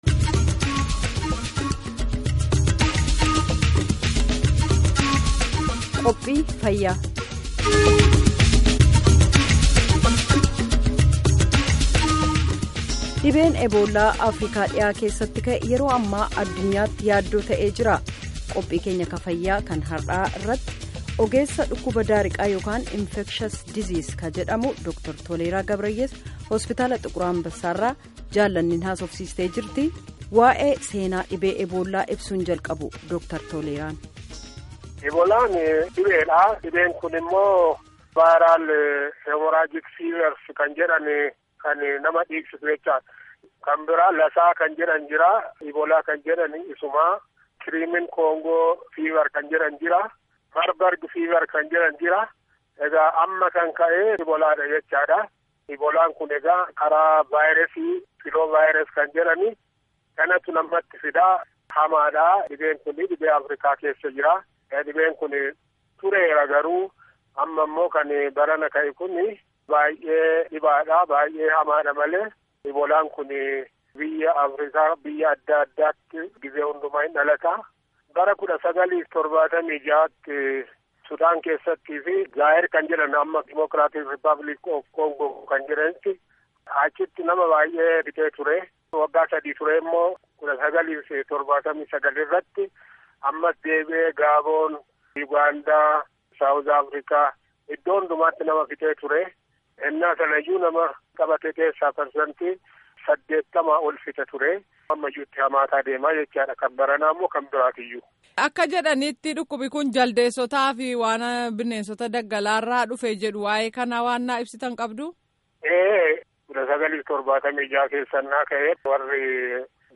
Gaaffii fi Deebii